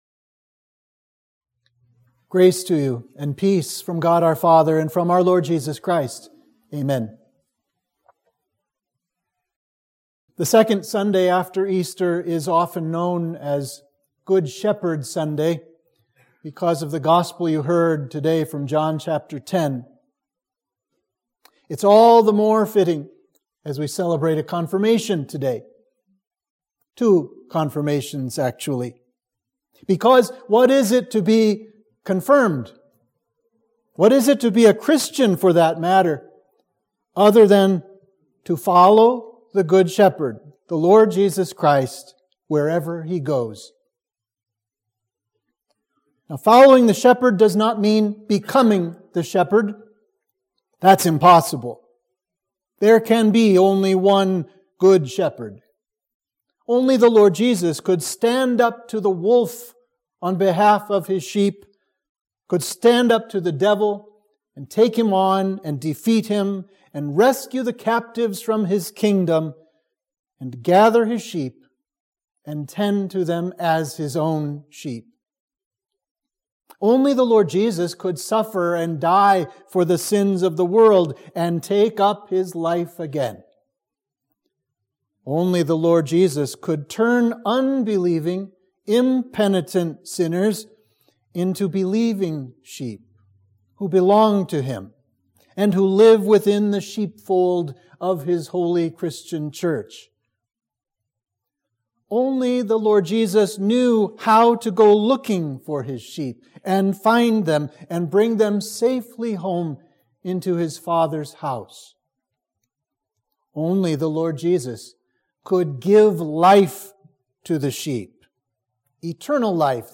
Sermon for Easter 2 – Misericordias Domini